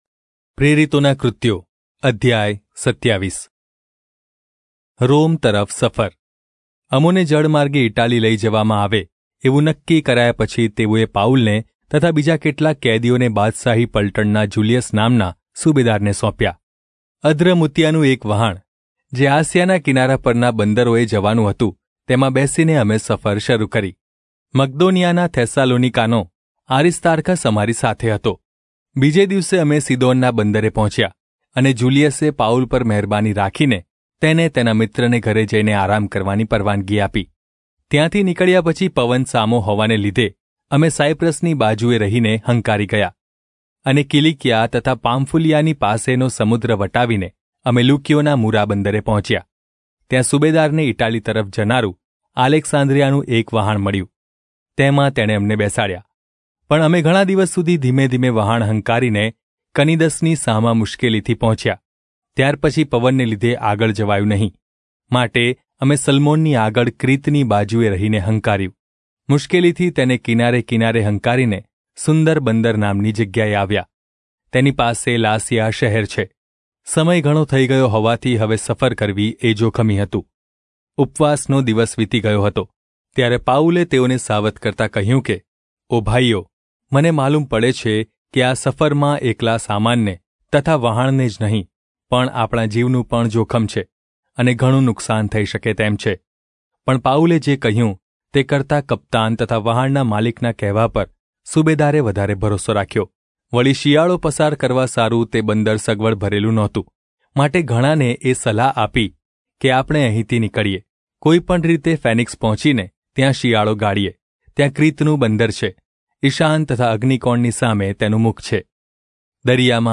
Gujarati Audio Bible - Acts 5 in Irvgu bible version